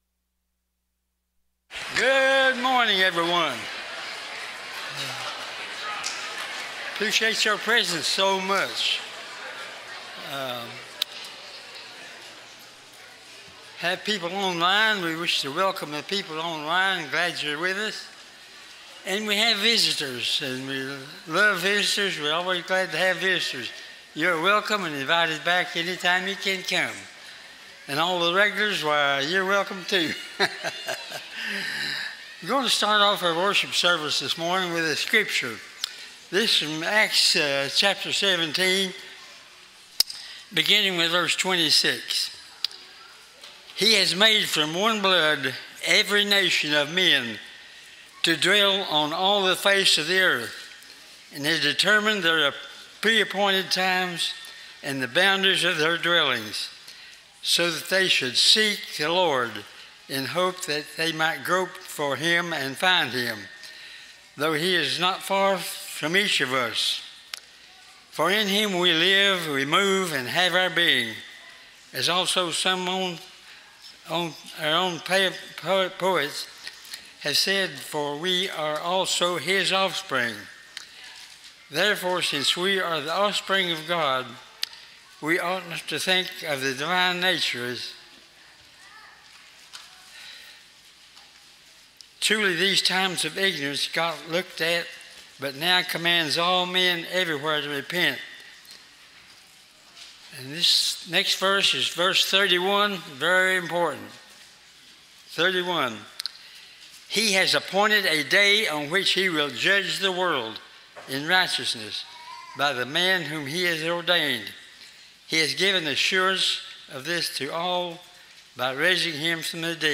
Psalms 100:5, English Standard Version Series: Sunday AM Service